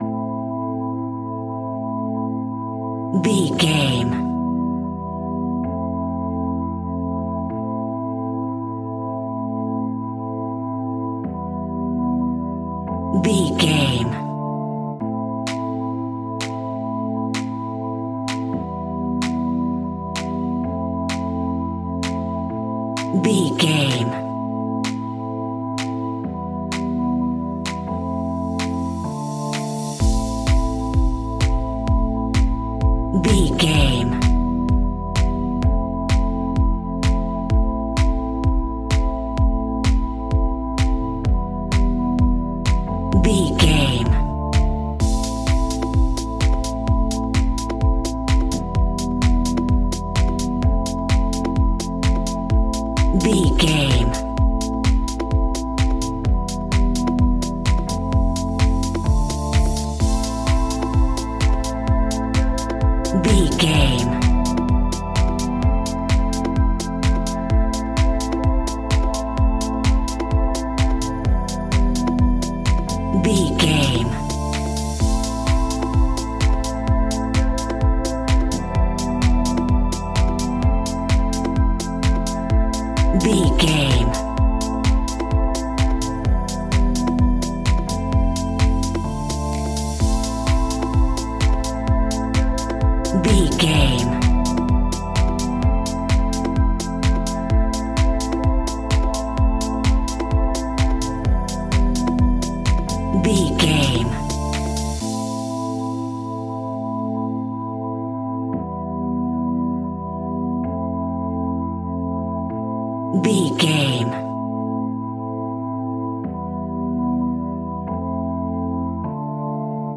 Aeolian/Minor
dark
futuristic
groovy
synthesiser
drum machine
electro house
progressive house
funky house
instrumentals
synth leads
synth bass